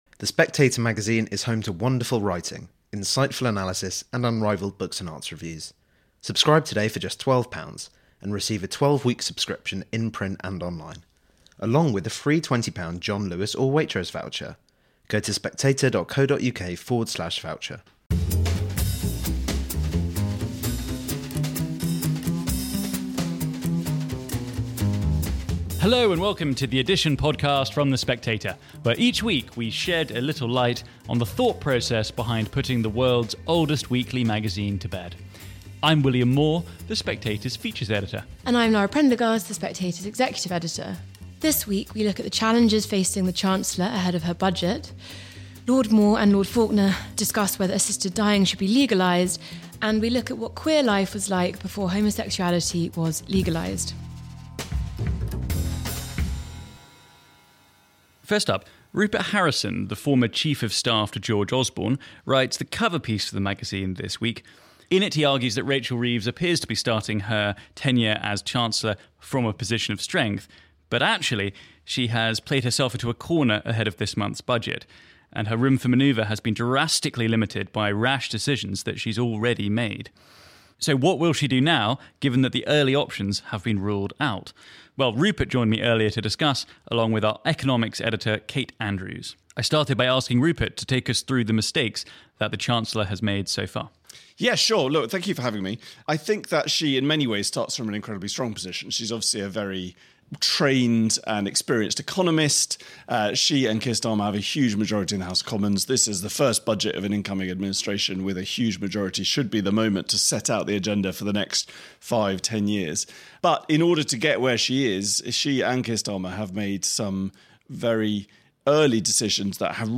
Reeves's gambit, a debate on assisted dying & queer life in postwar Britain